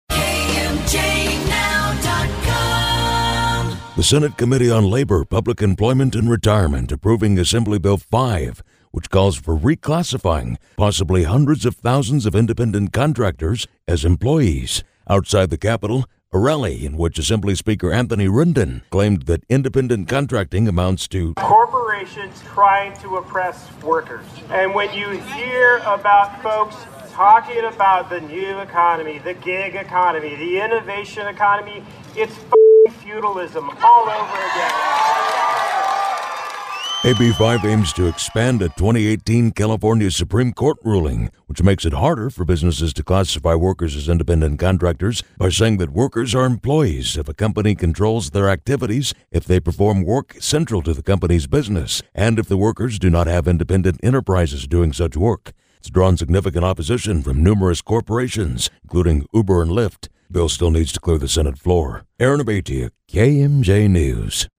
Outside the California State Capitol, a rally was held, in which Assembly Speaker Anthony Rendon claimed that independent contracting amounts to “corporations trying to oppress workers.”